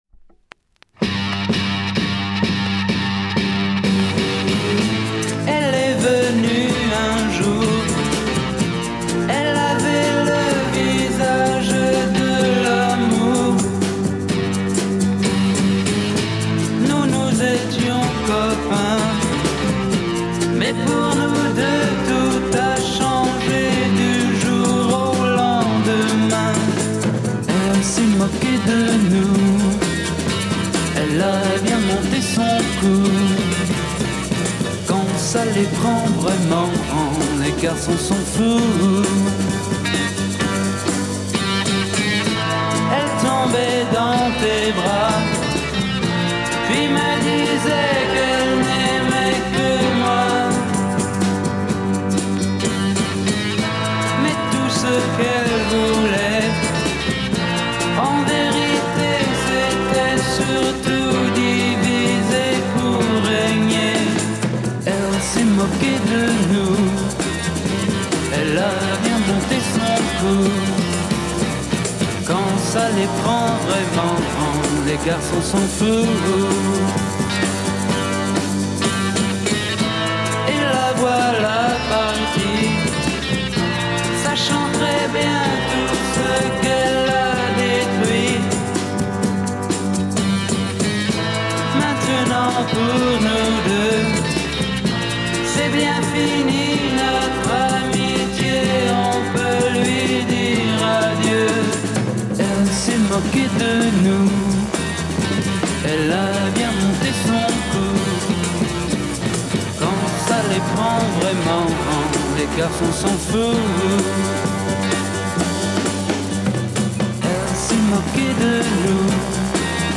Killer French freakbeat Fuzz
in French vocal, Monster Fuzz guitar !!!